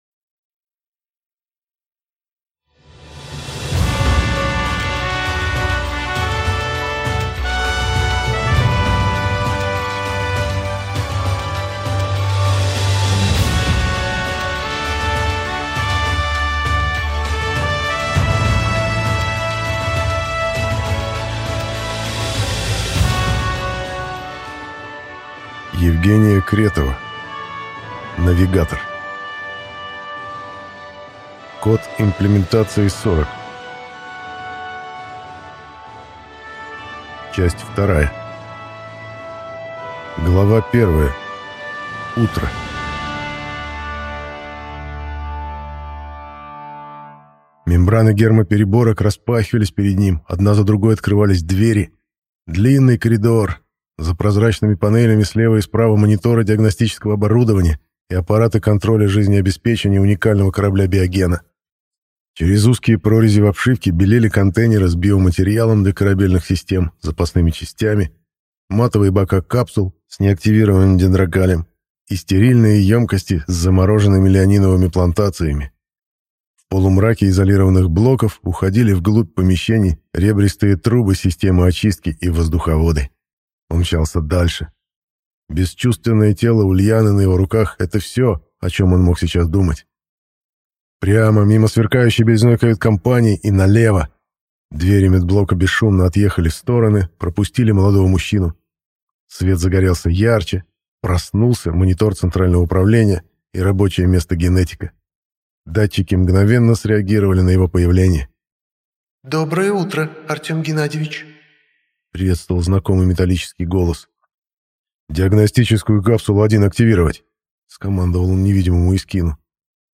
Аудиокнига Навигатор. Код имплементации: 40. Часть 2 | Библиотека аудиокниг